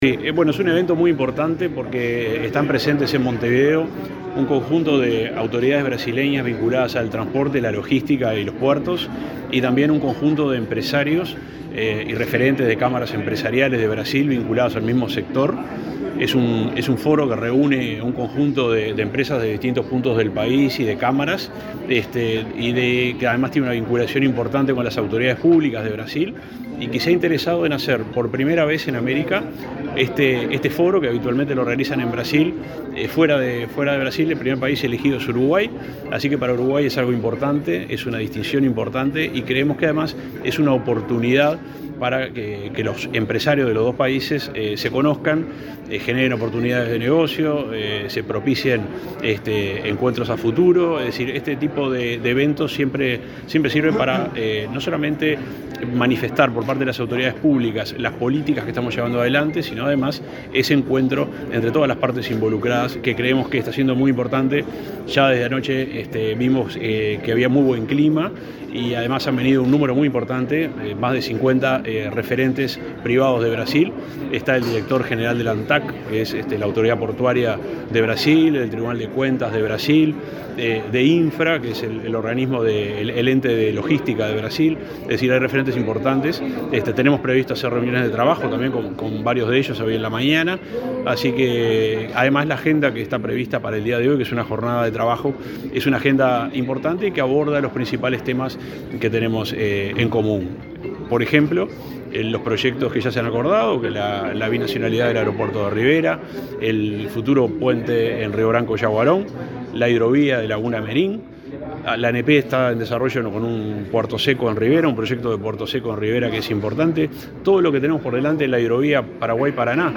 Entrevista al subsecretario de Transporte, José Luis Olaizola
Entrevista al subsecretario de Transporte, José Luis Olaizola 12/09/2023 Compartir Facebook X Copiar enlace WhatsApp LinkedIn El subsecretario de Transporte, Juan José Olaizola, dialogó con Comunicación Presidencial, luego de participar, este martes 12 en Montevideo, en el foro Mercosul Export.